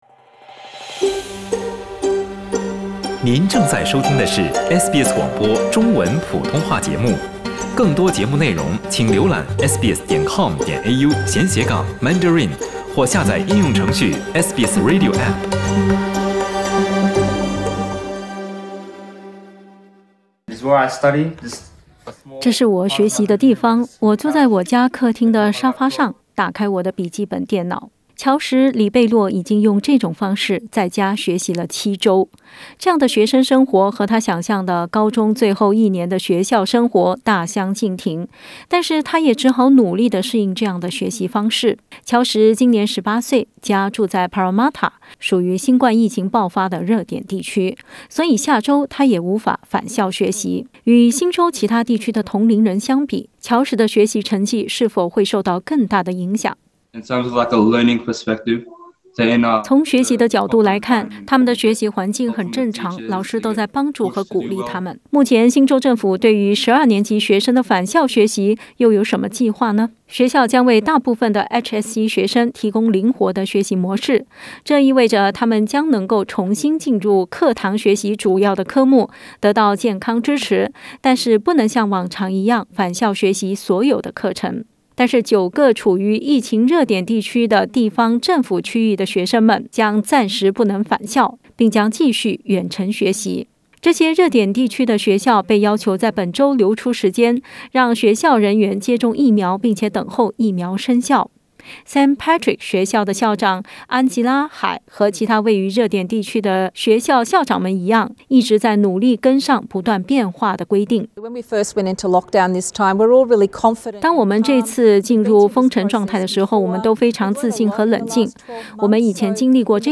在悉尼，新冠疫情并未受到有效控制，这对于即将参加全国高中毕业考试HSC的十二年级学生来说意味着什么？（点击图片收听详细报道）